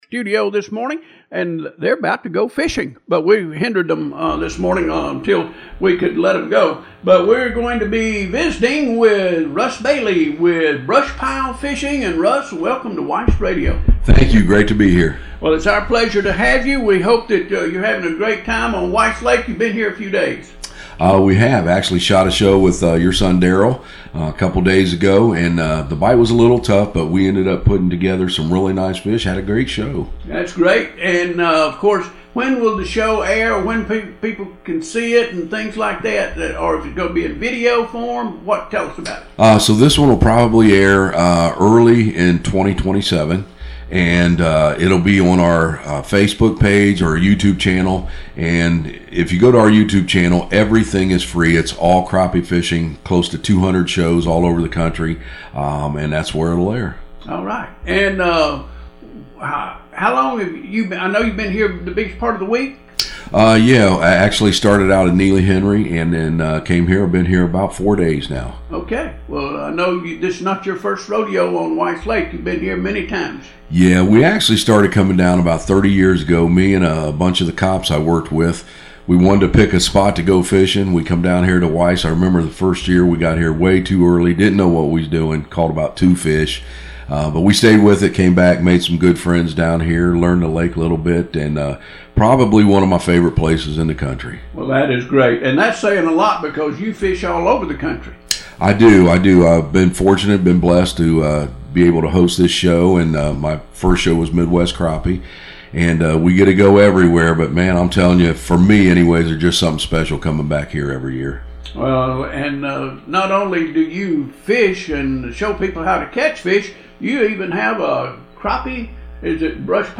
BrushPile-Fishing-on-air-interview-.mp3